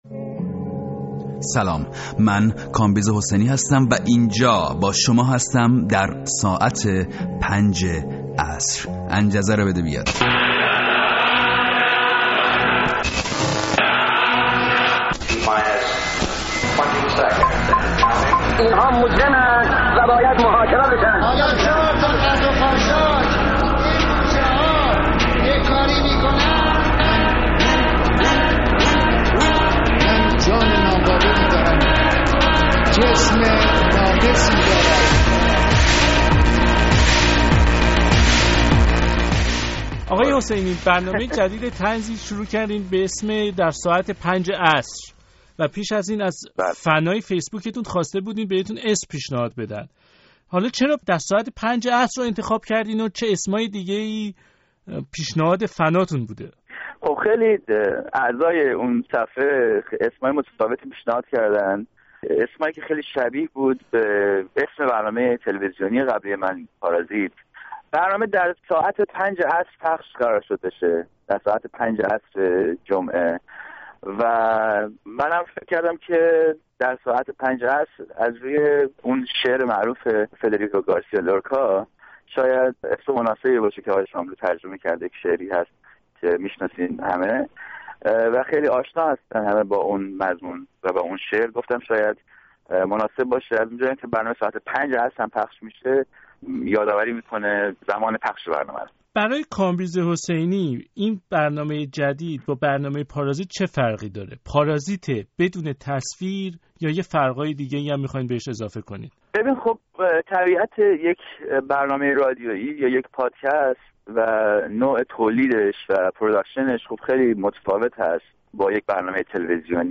گفتگوی رادیو فردا با کامبیز حسینی در مورد برنامه جدیدش